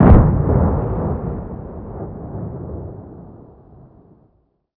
torn_Bomb2.wav